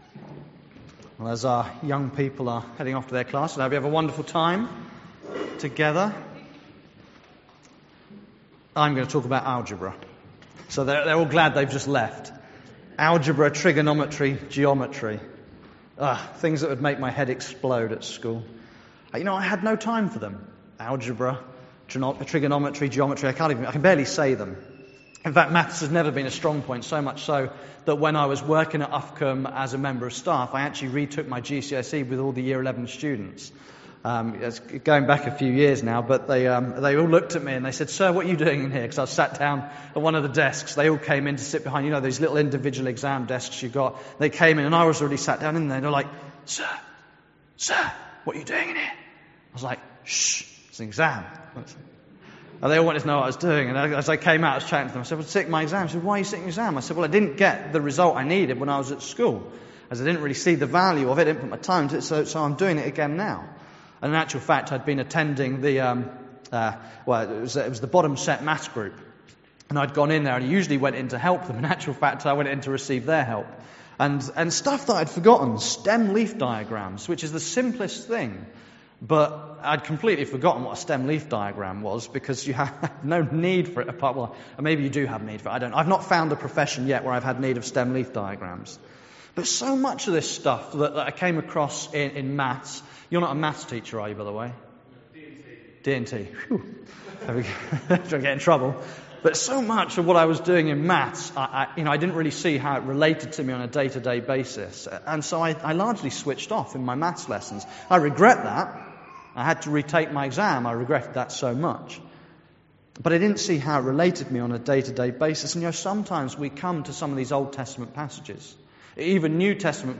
An audio file of the service is available to listen to.